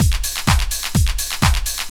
Motion Beat_126.wav